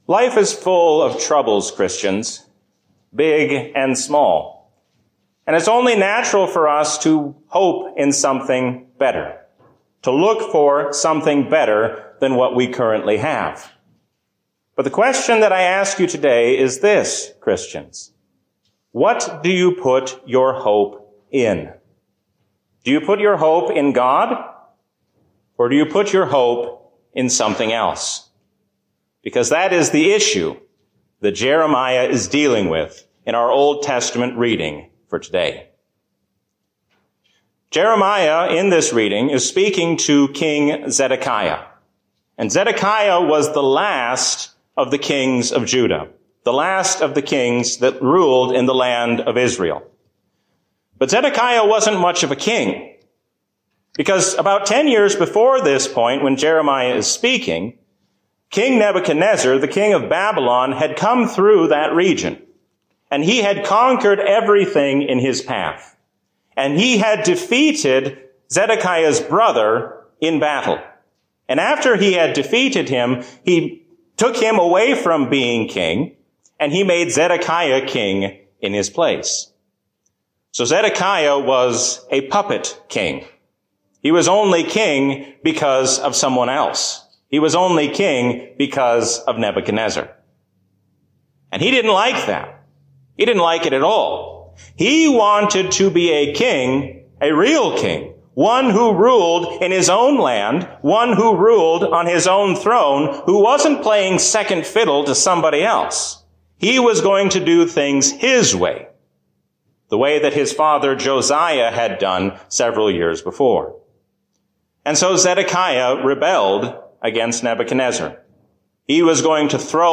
A sermon from the season "Trinity 2024." The ascension of Christ strengthens us to carry out the work of the Church while we wait for His coming.